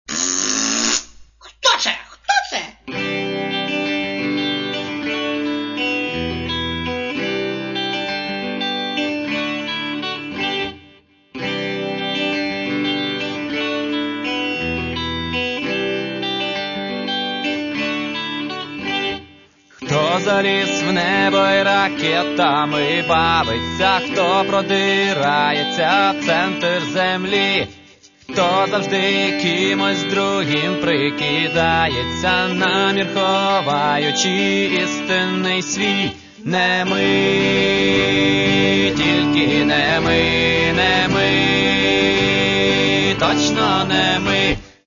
Catalogue -> Rock & Alternative -> Punk
Simple rock.